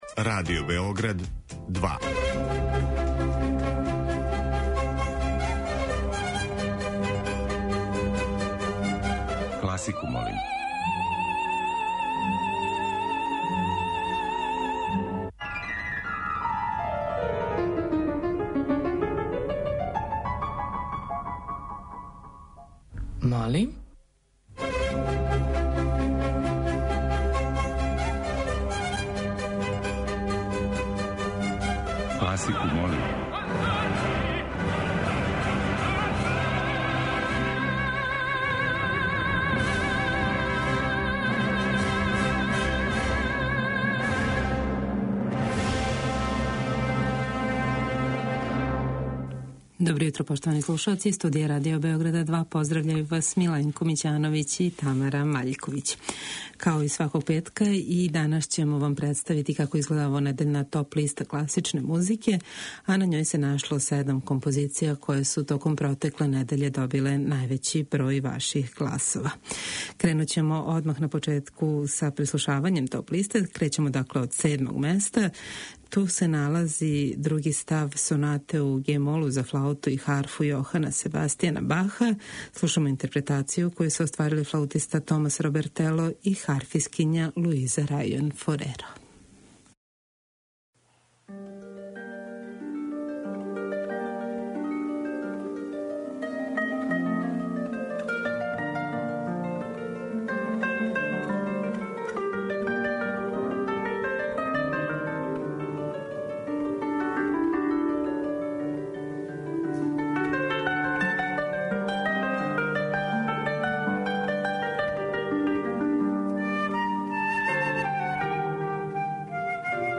Петак је дан када вам представљамо недељну топ-листу класичне музике.